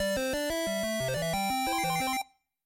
Un clásico sonido de inicio de videojuego retro de 8 bits, perfecto para contenido de juegos y nostalgia.
8Bit Video Game Startup 01
8bit-video-game-startup-01.mp3